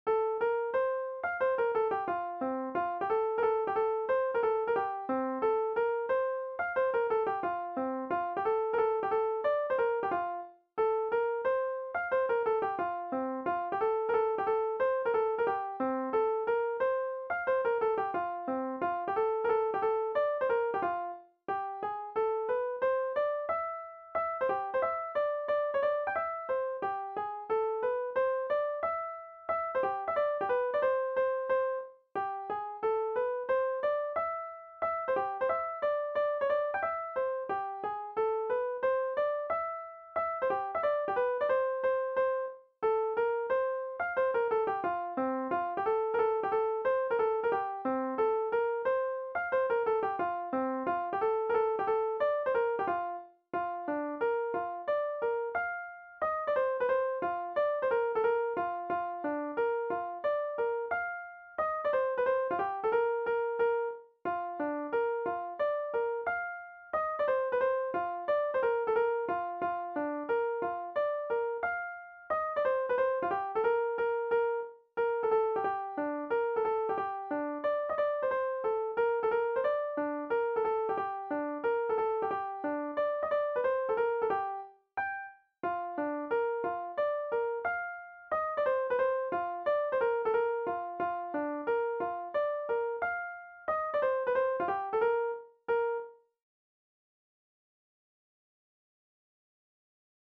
Instrumental
danse : scottish